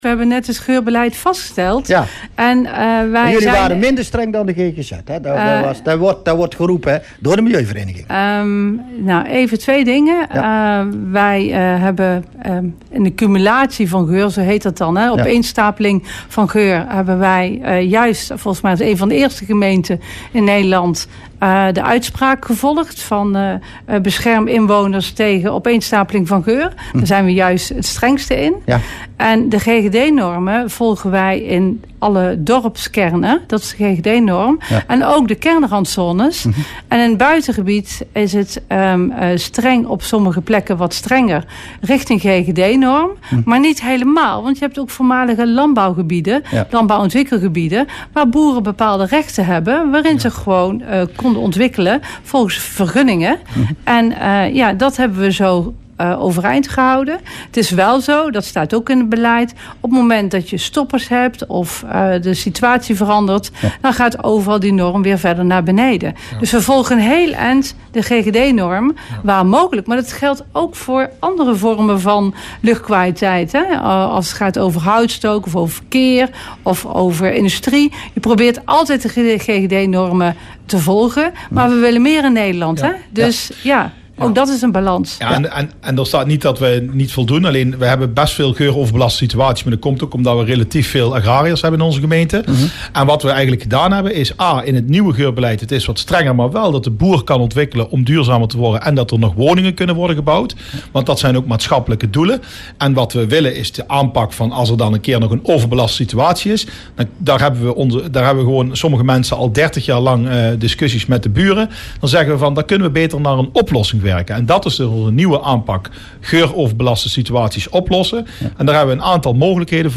Dat zei wethouder Mark Janssen-van Gaal in Regelkevers, het politieke praatprogramma op Omroep Land van Cuijk.